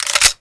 auto_first_viewfinder.wav